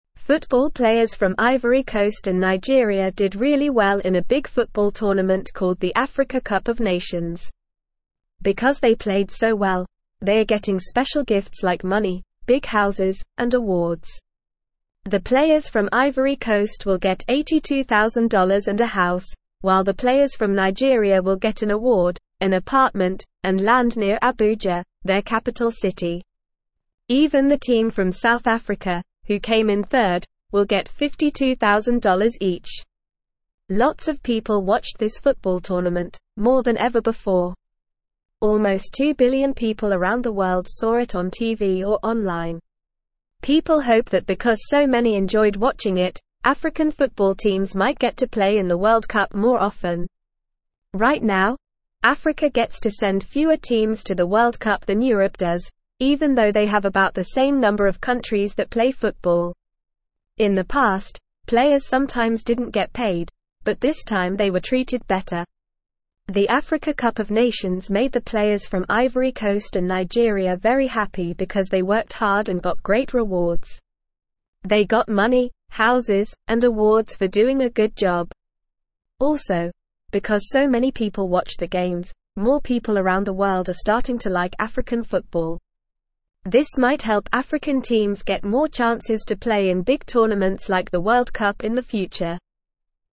Normal
English-Newsroom-Lower-Intermediate-NORMAL-Reading-Special-Gifts-for-African-Football-Players-after-Big-Game.mp3